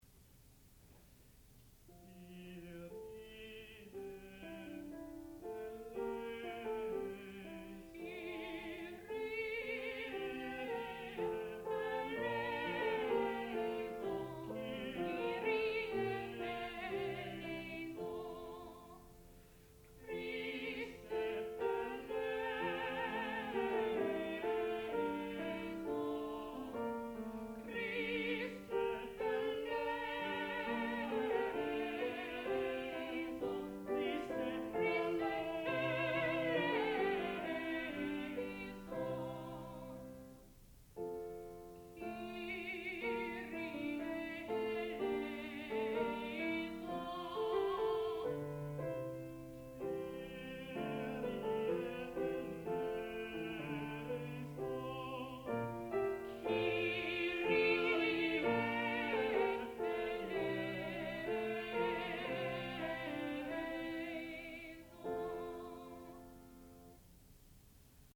sound recording-musical
classical music
piano
tenor